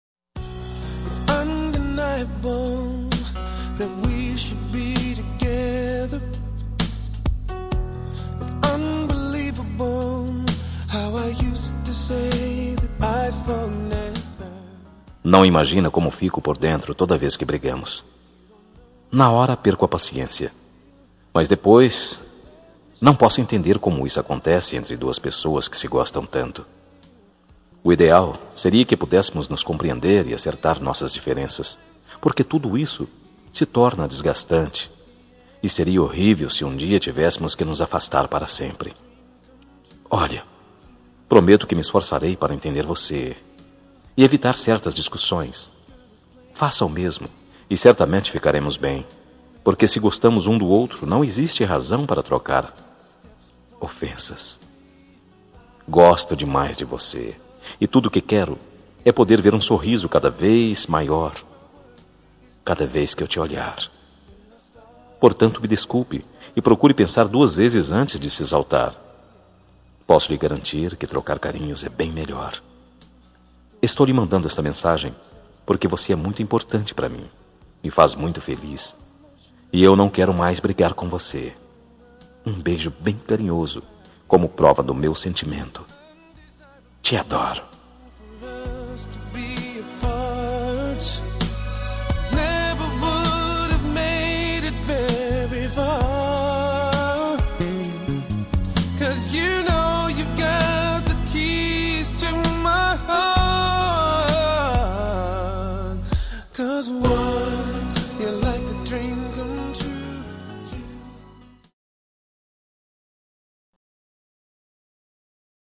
Telemensagem de Desculpas – Voz Masculina – Cód: 418